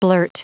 Prononciation du mot blurt en anglais (fichier audio)
Prononciation du mot : blurt